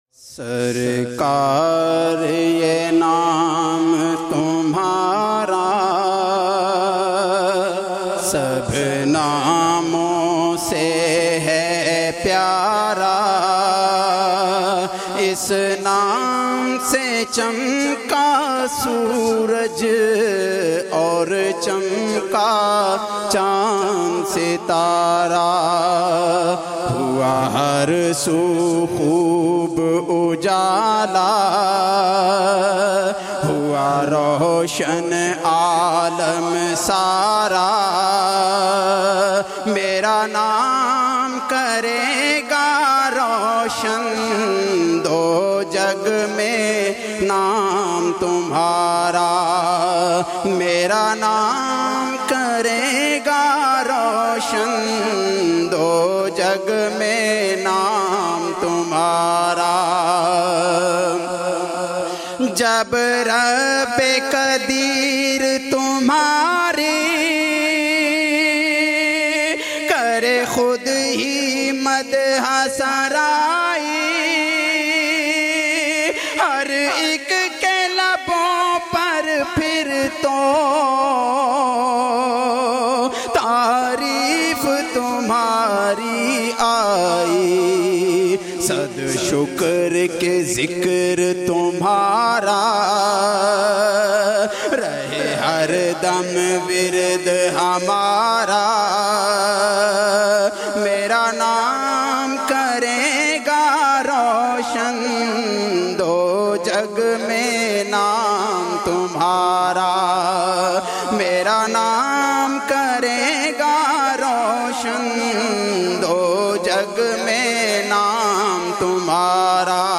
آڈیو نعتیں